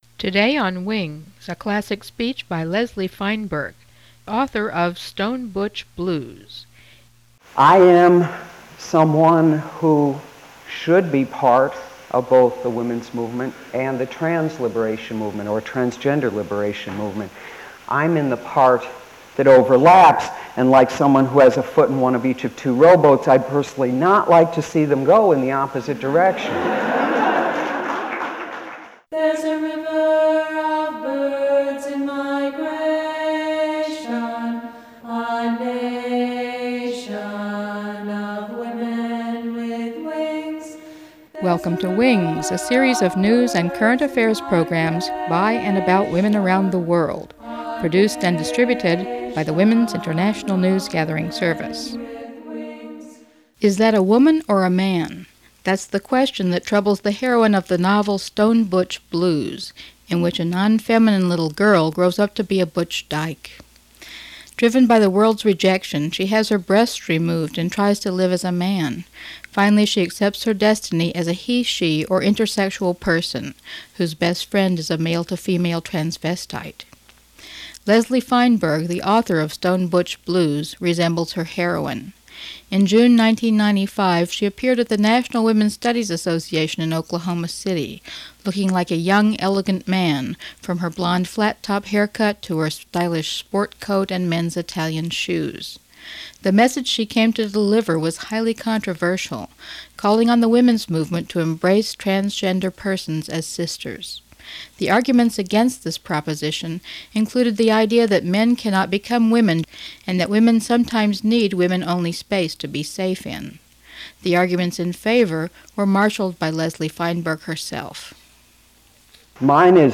Leslie Feinberg addresses US National Women's Studies Association 1995